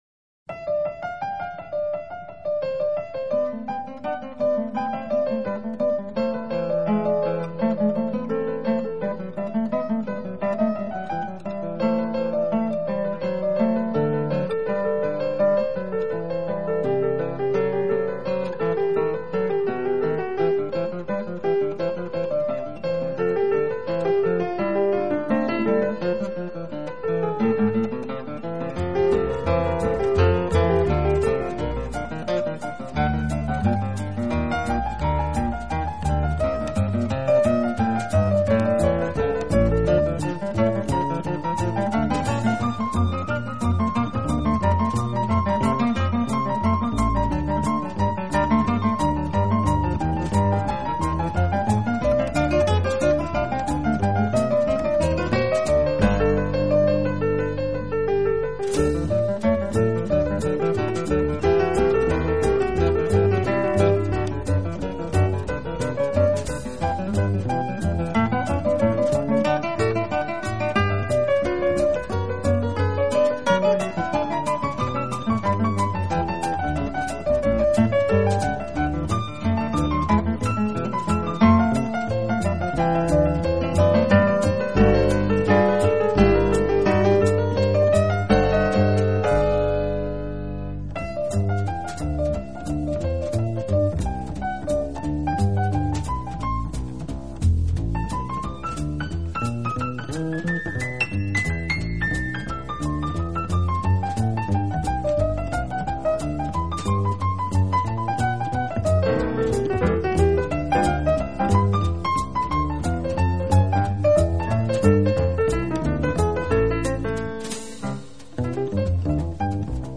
这是一张十分另类的跨界作品。